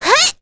Miruru-Vox_Attack3.wav